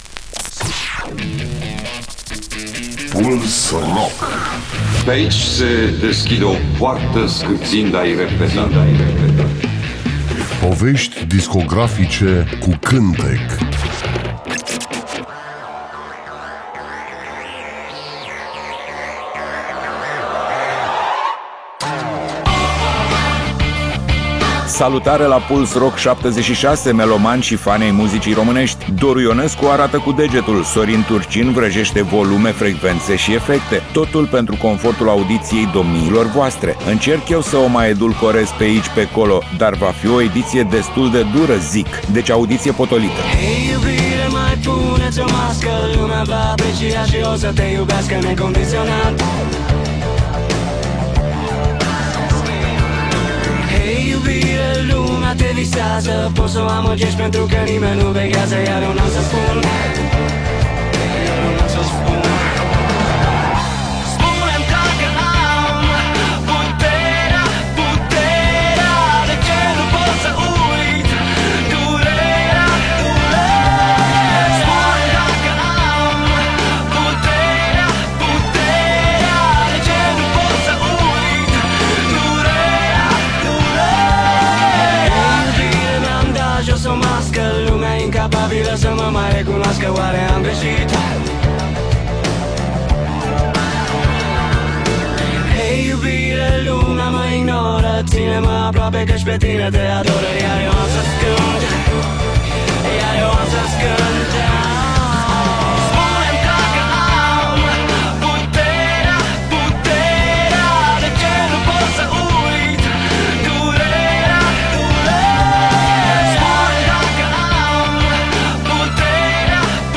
Emisiunea se numește Puls Rock și jonglează cu artiștii noștri rock, folk, uneori chiar jazz. Voi căuta lucruri interesante, valoroase, care au scăpat atenției publicului. Fiecare melodie pe care o voi prezenta are o poveste.